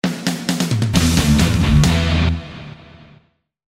Largo [0-10] - - rock - transition - riff - virgule